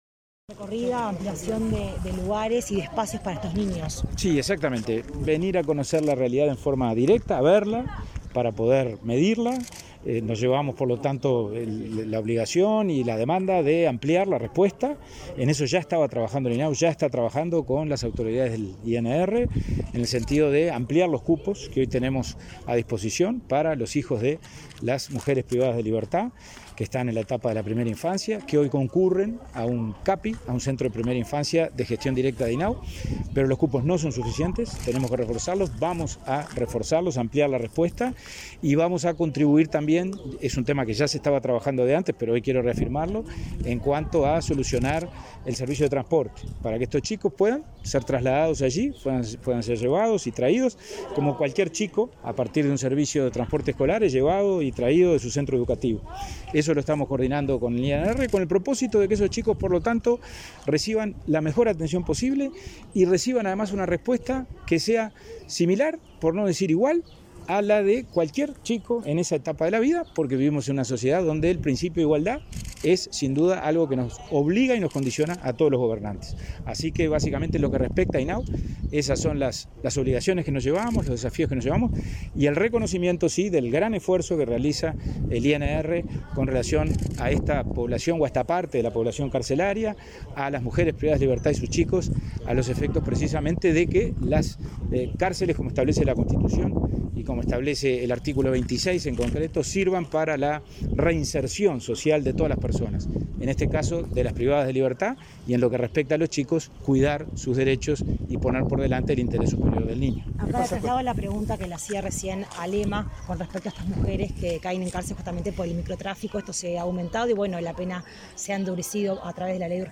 Declaraciones a la prensa del presidente del INAU, Pablo Abdala
Declaraciones a la prensa del presidente del INAU, Pablo Abdala 26/08/2021 Compartir Facebook X Copiar enlace WhatsApp LinkedIn Tras participar en la recorrida por la Unidad 9 del Instituto Nacional de Rehabilitación, este 26 de agosto, el titular del Instituto del Niño y el Adolescente del Uruguay (INAU) efectuó declaraciones a la prensa.